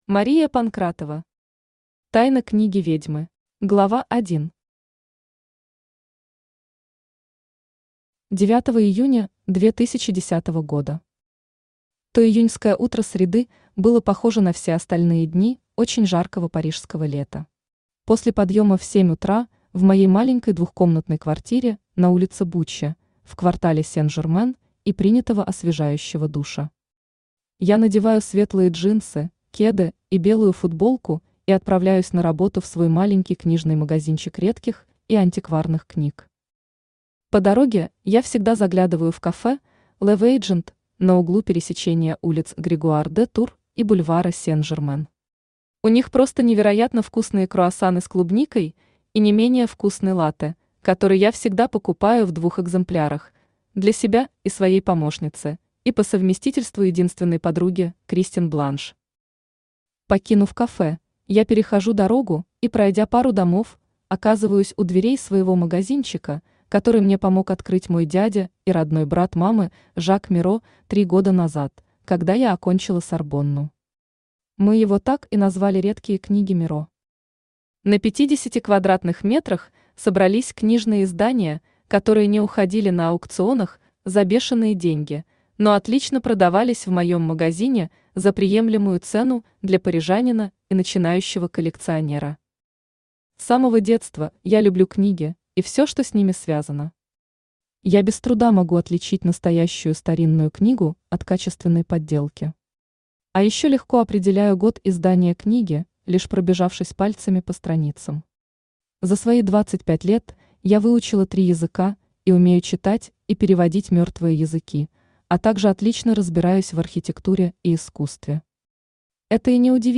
Аудиокнига Тайна Книги Ведьмы | Библиотека аудиокниг
Aудиокнига Тайна Книги Ведьмы Автор Мария Панкратова Читает аудиокнигу Авточтец ЛитРес.